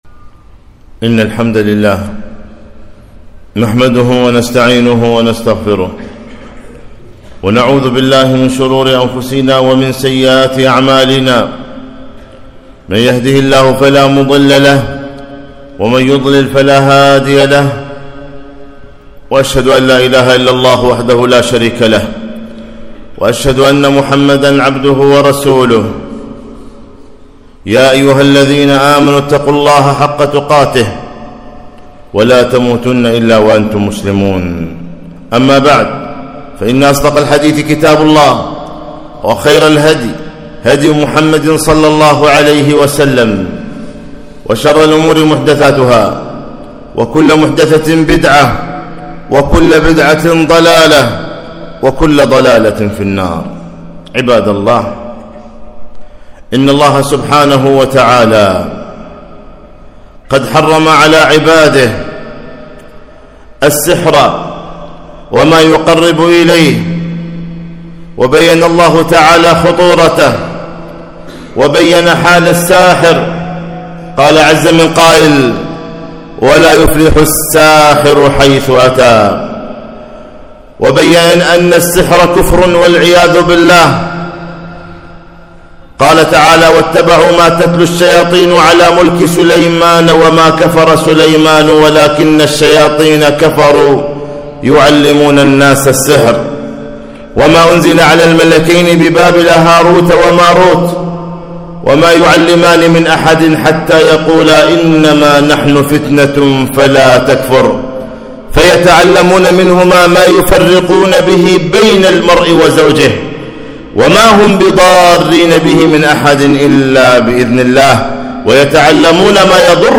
خطبة - التحذير من السحرة والعرافيين - دروس الكويت